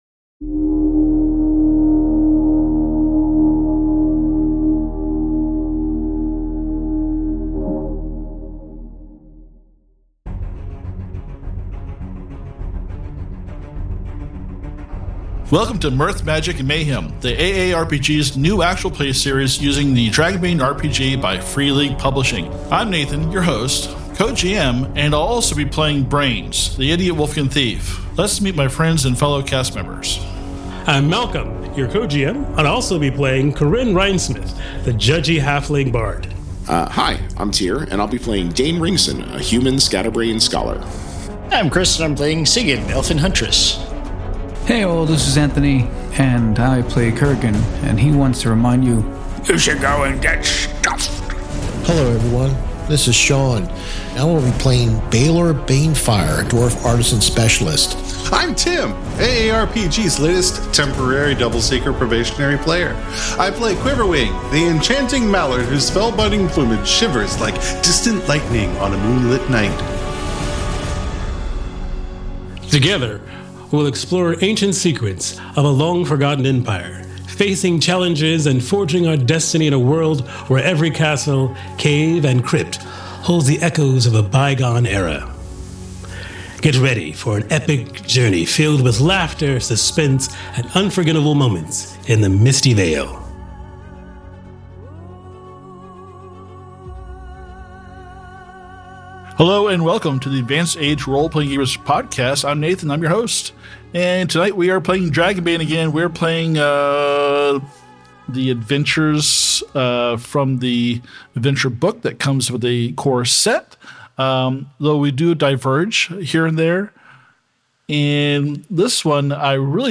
Step into the rich tapestry of Dragonbane RPG as our actual play podcast unfolds in the Misty Vale, a region steeped in history as the heart of the Dragon Empire thousands of years ago.